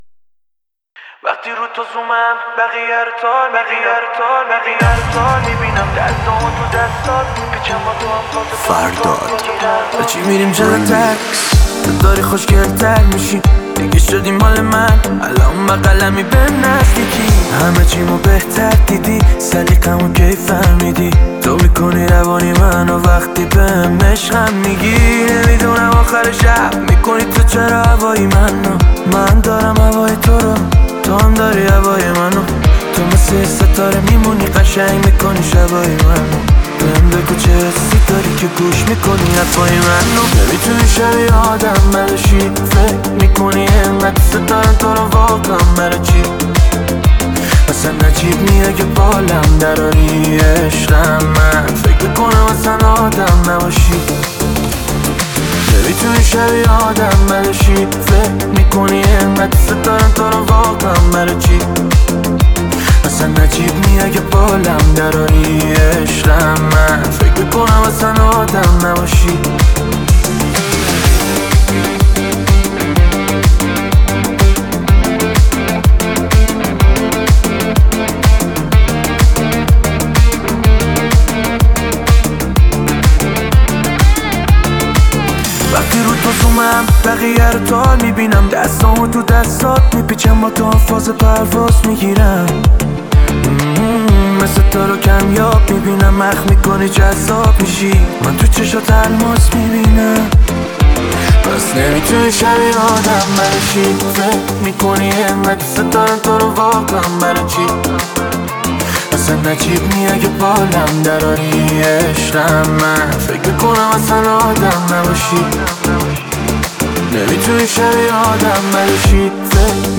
ریمیکس تند بیس دار اینستاگرام